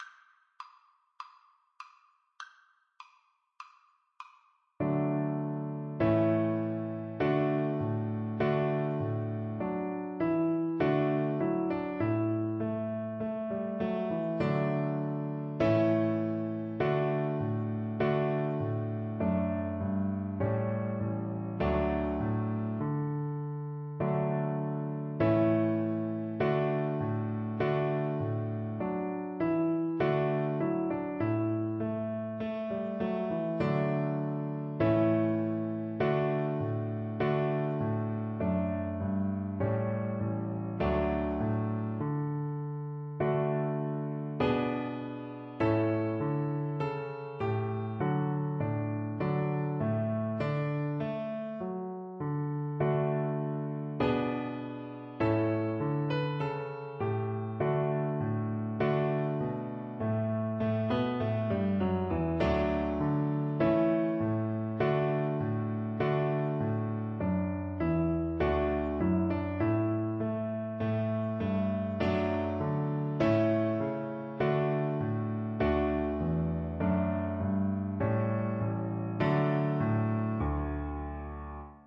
Play (or use space bar on your keyboard) Pause Music Playalong - Piano Accompaniment transpose reset tempo print settings full screen
Cello
4/4 (View more 4/4 Music)
Andante = c. 100
Arrangement for Cello and Piano
D major (Sounding Pitch) (View more D major Music for Cello )
Classical (View more Classical Cello Music)